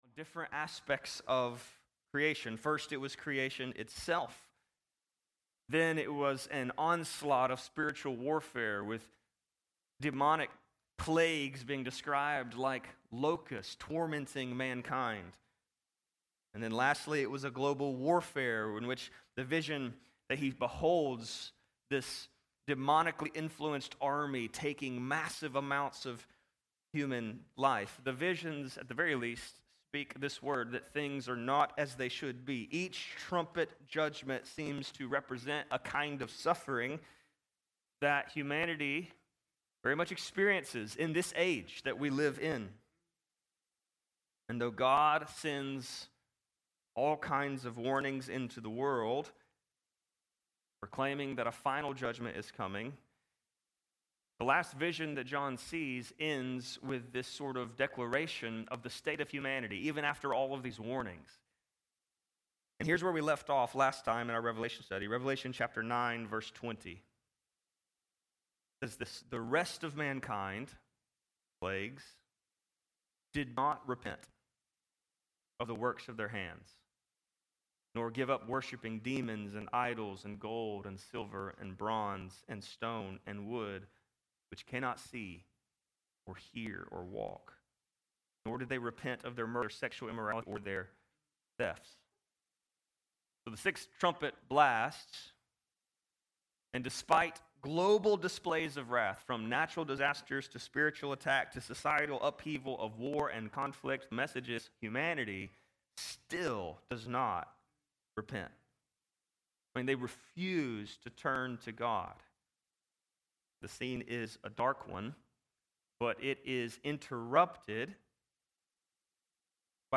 The first few seconds were not recorded.